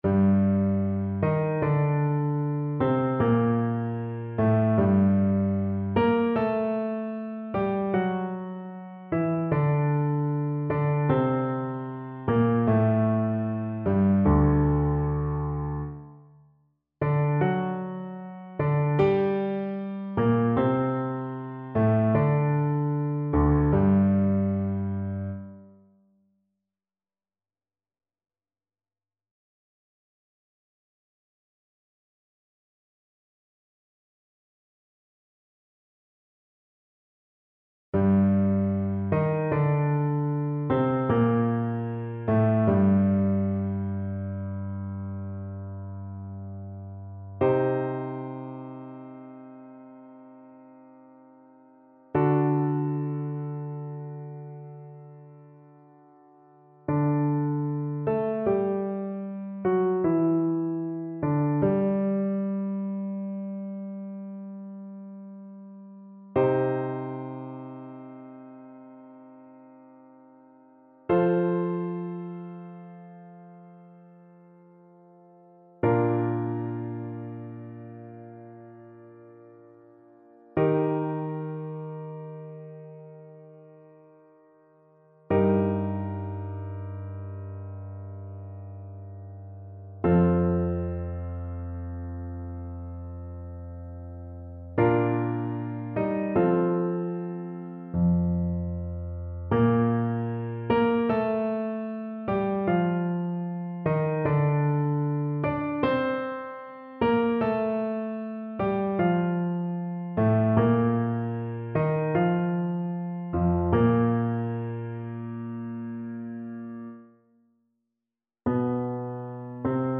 Play (or use space bar on your keyboard) Pause Music Playalong - Piano Accompaniment Playalong Band Accompaniment not yet available transpose reset tempo print settings full screen
G minor (Sounding Pitch) A minor (Clarinet in Bb) (View more G minor Music for Clarinet )
3/4 (View more 3/4 Music)
II: Largo =38
Classical (View more Classical Clarinet Music)